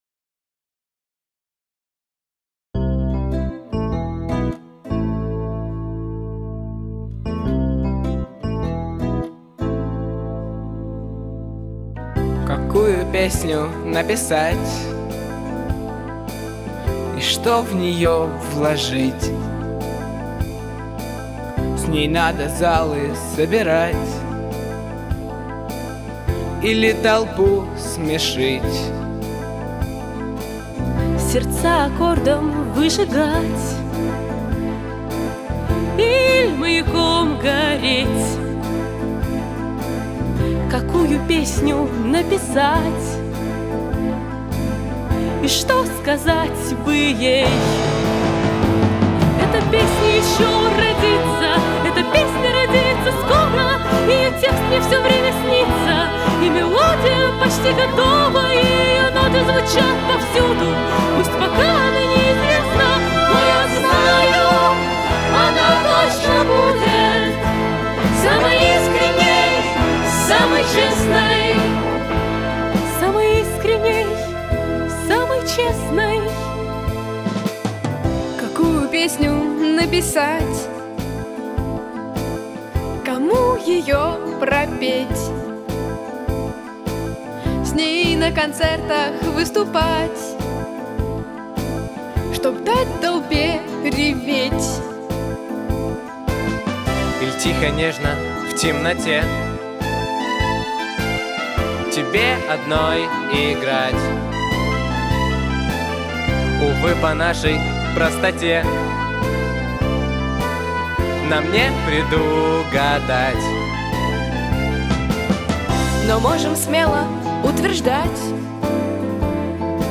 Записано в студии